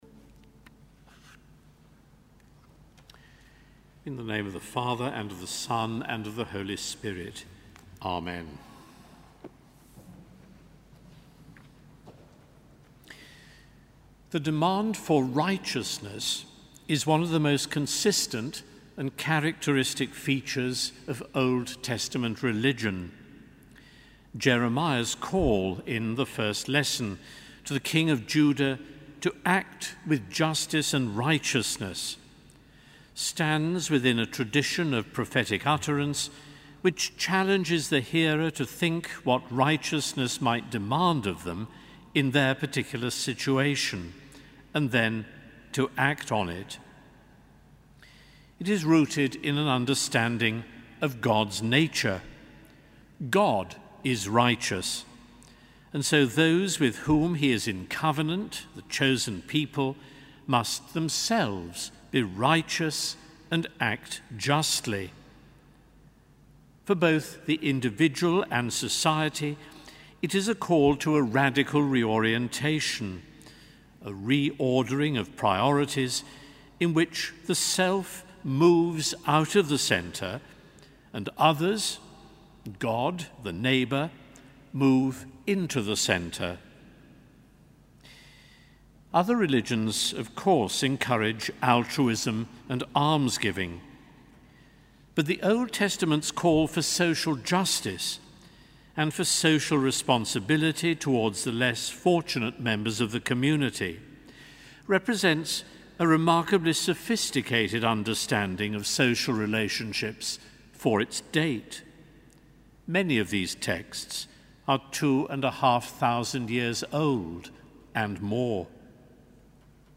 Sermon: Mattins 16 March 2014